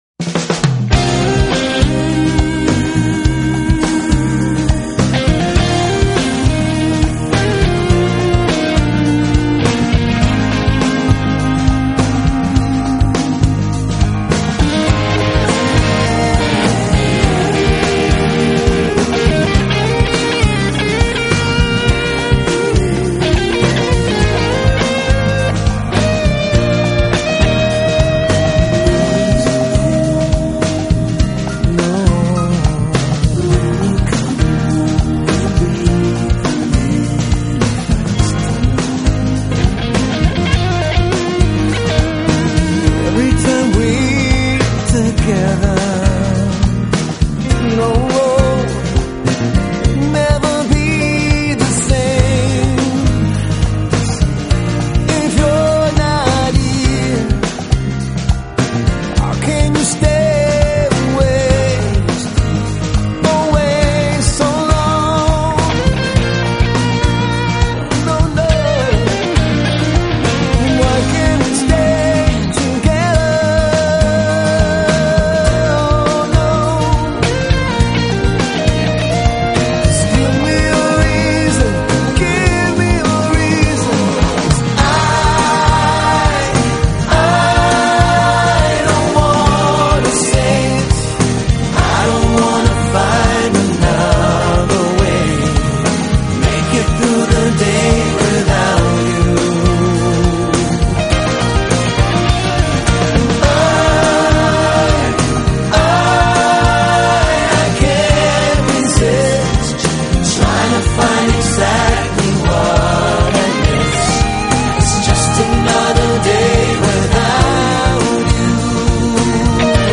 音乐类型: Smooth Jazz